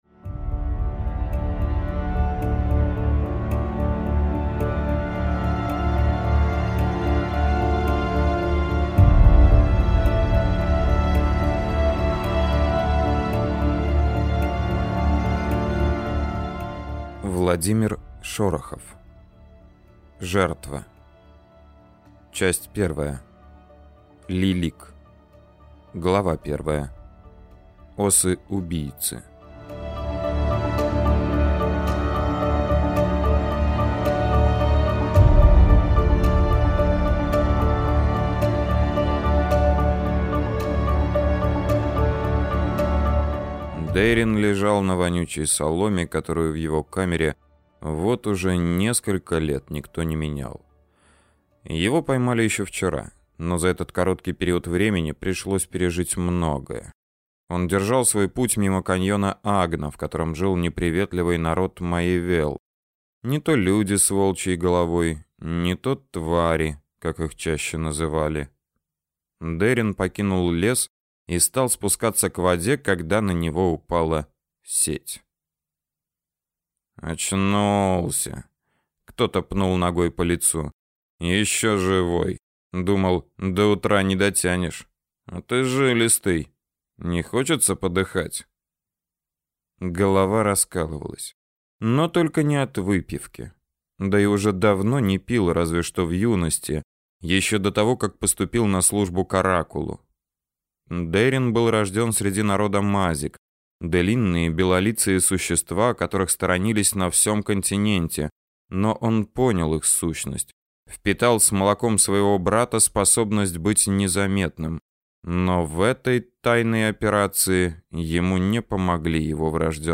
Аудиокнига Жертва | Библиотека аудиокниг